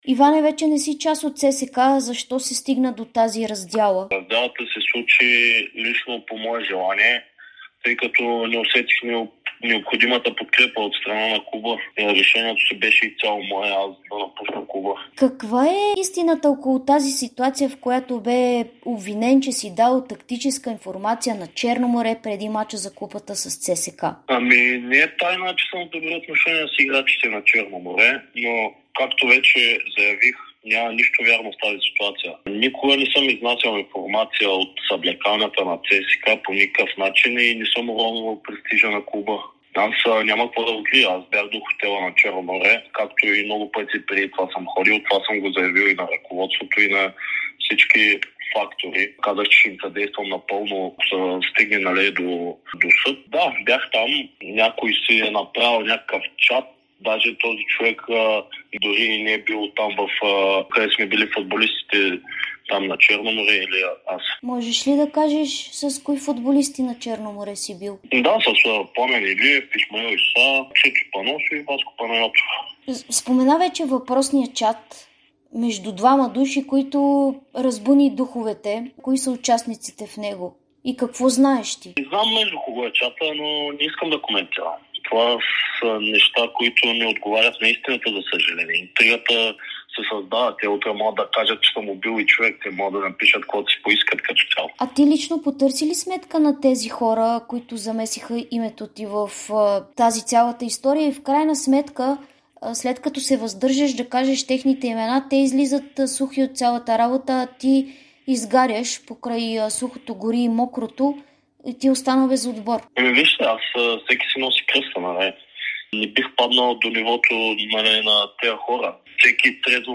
Доскорошният вратар на ЦСКА – Иван Дюлгеров, даде ексклузивно интервю пред Дарик радио и Dsport броени дни, след като „червените“ обявиха раздялата с него.